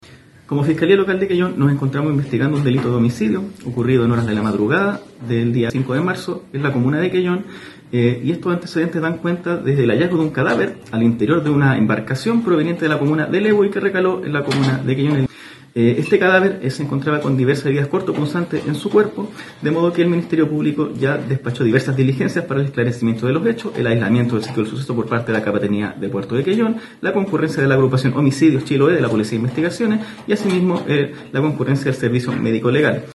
El fiscal Fabián Fernández de la fiscalía local de Quellón confirmó las diligencias que se instruyeron en el puerto artesanal de esa comuna.